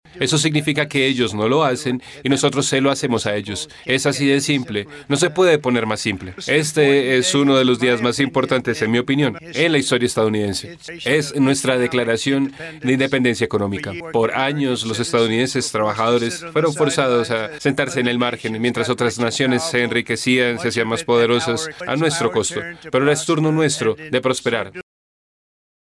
De esta manera, son su tono imponente de siempre, en el jardín de rosas de la Casa Blanca, el mandatario estadounidense partió su discurso hablando de historia, del aprovechamiento y asalto a la economía del país norteamericano, y el rol subsidiario que ha tenido Estados Unidos con otros.